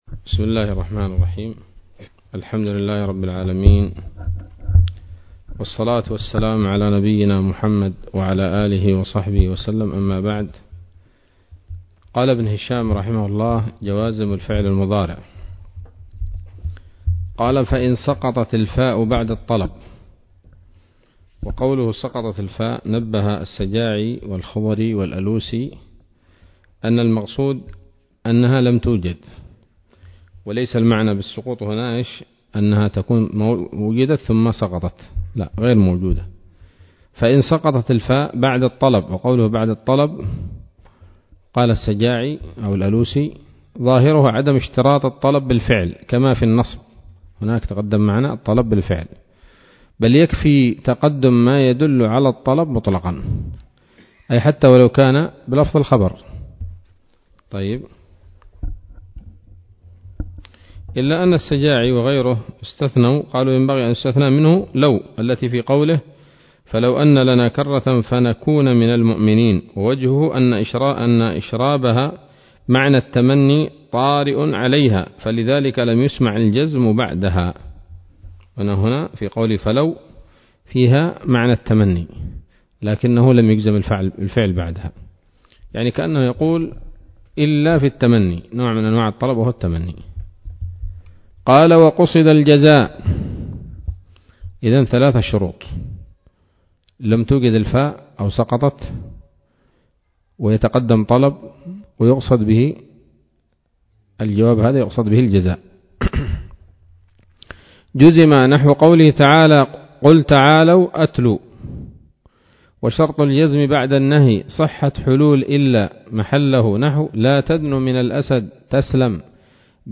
الدرس الرابع والثلاثون من شرح قطر الندى وبل الصدى [1444هـ]